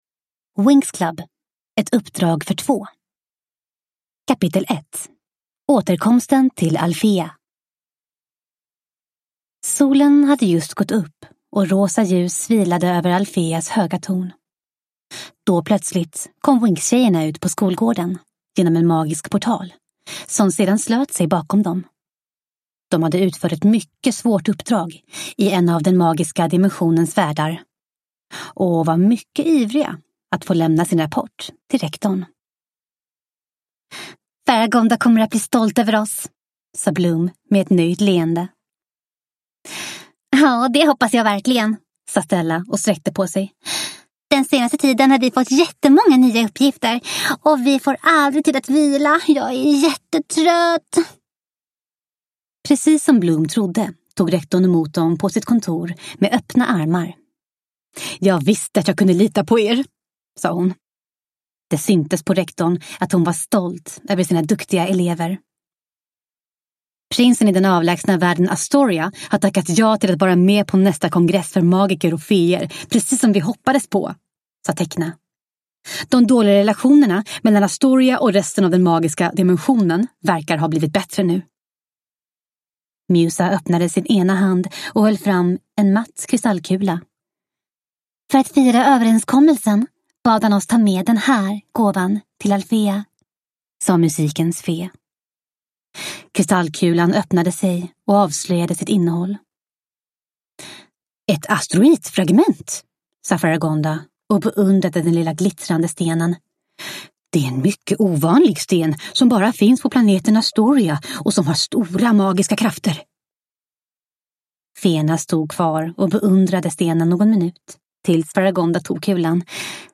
Winx Club: Ett uppdrag för två – Ljudbok